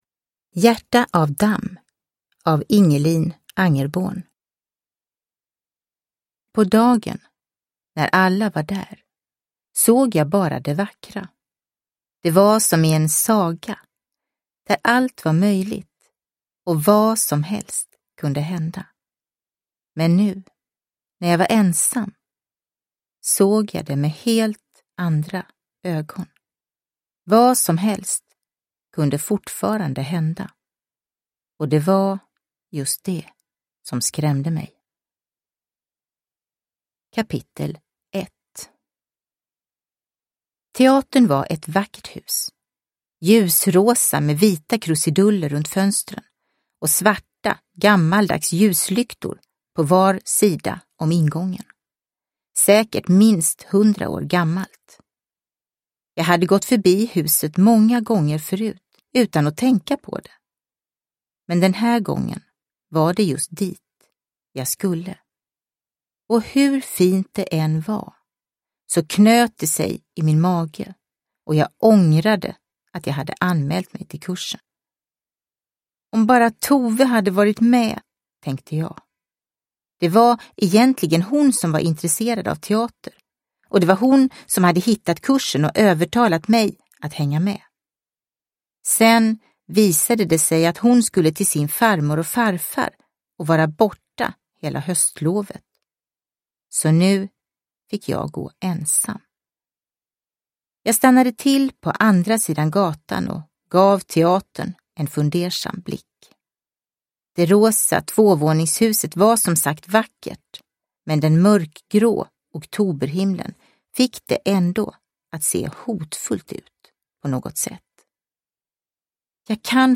Hjärta av damm – Ljudbok – Laddas ner